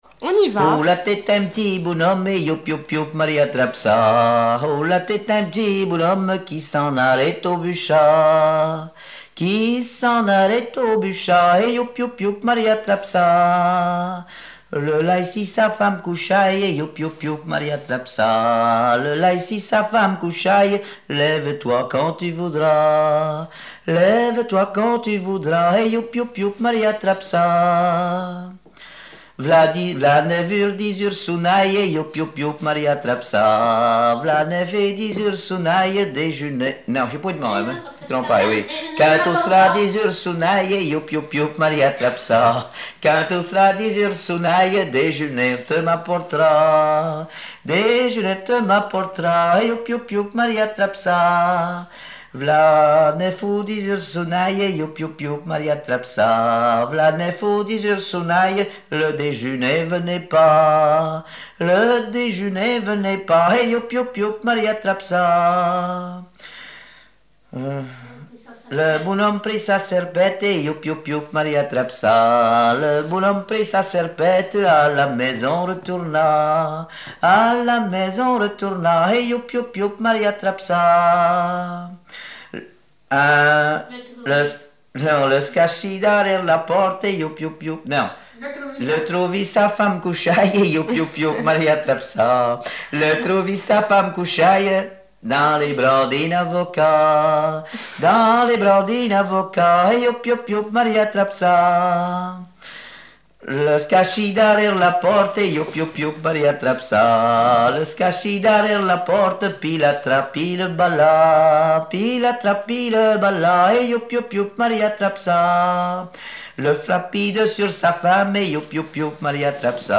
ronde : grand'danse
Répertoire de chansons traditionnelles et populaires
Pièce musicale inédite